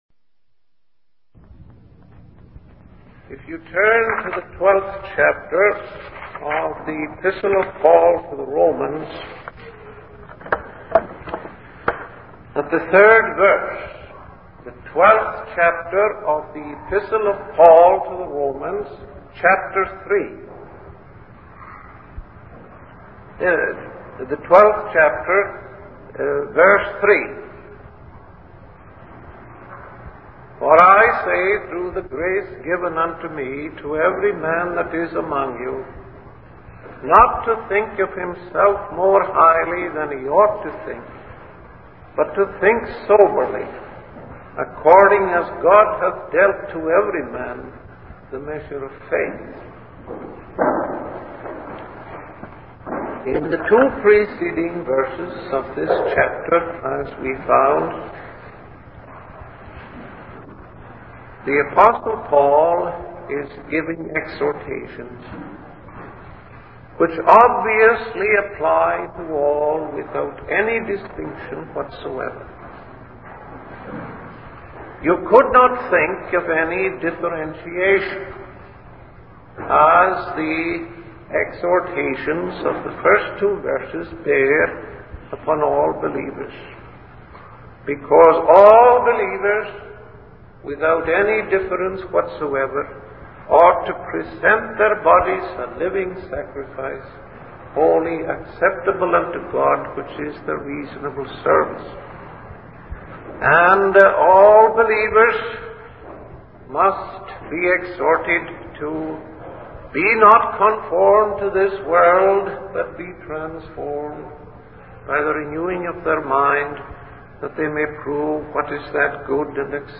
In this sermon, the Apostle Paul's plea to believers is based on the tender compassion of God. He urges all believers to present their bodies as a living sacrifice, which is their reasonable service to God.